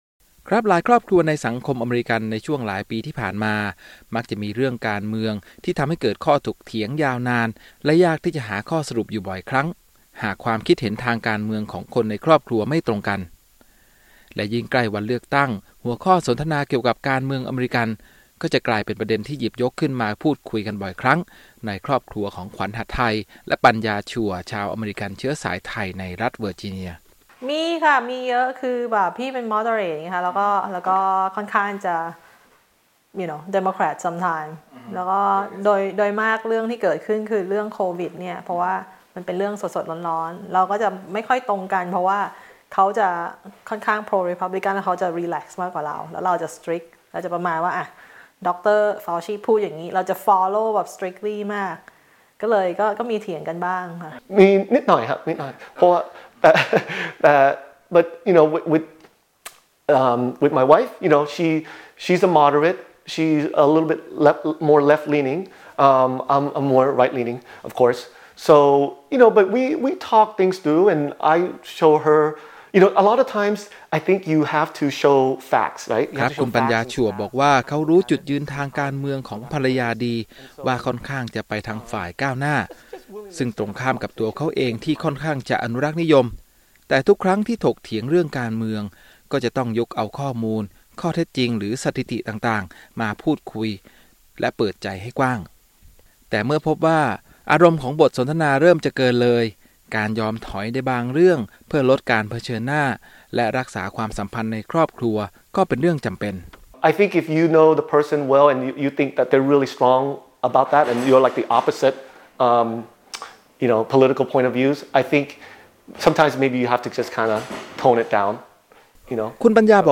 รายงานพิเศษ : ผู้คนเลือกข้าง-ครอบครัวเห็นต่าง ปมใหญ่การเมืองอเมริกัน